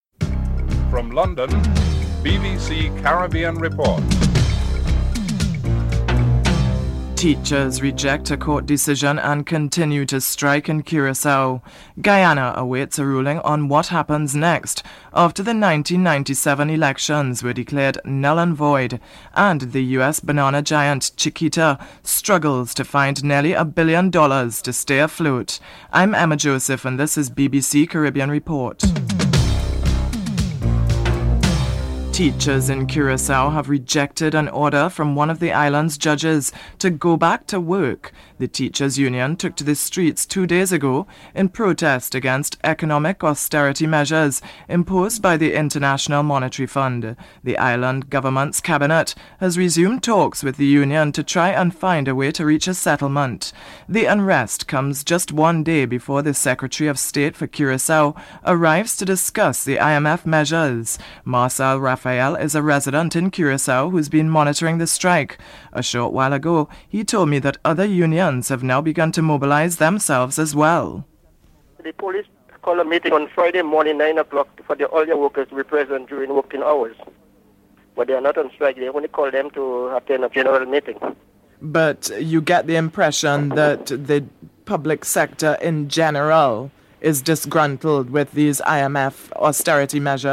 1. Headlines (00:00-00:31)
Prime Minister Pierre Charles is interviewed (05:26-08:11)